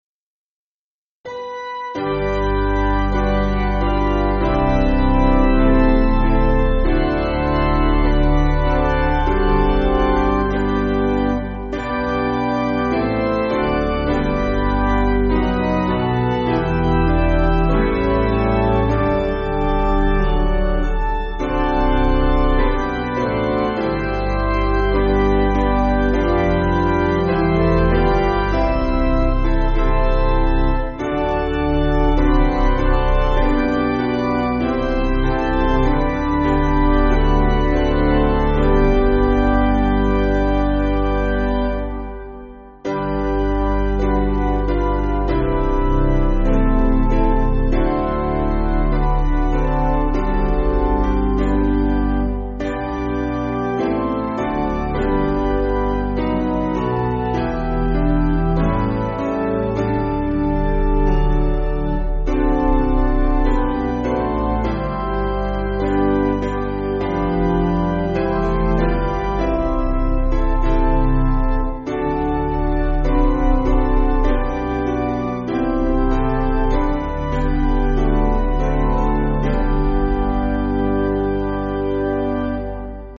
Basic Piano & Organ
(CM)   5/G